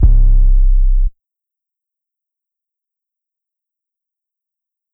808 (Futsal Shuffle).wav